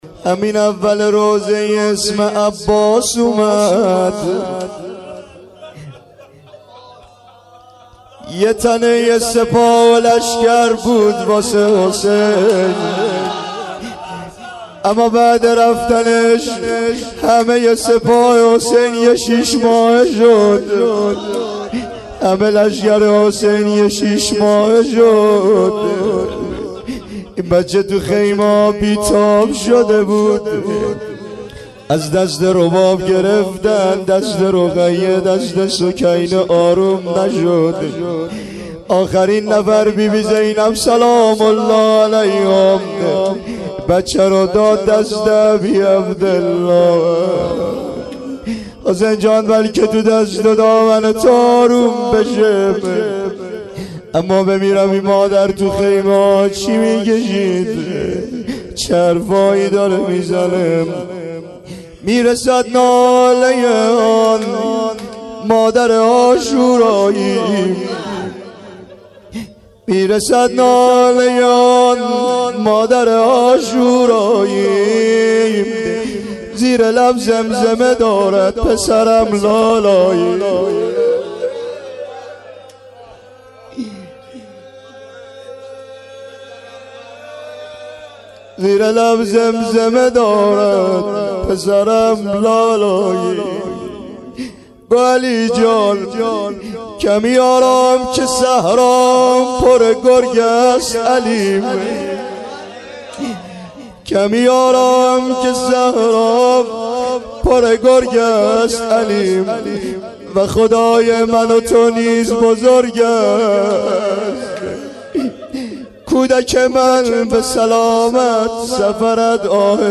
روضه حضرت ابالفضل( علیه السلام)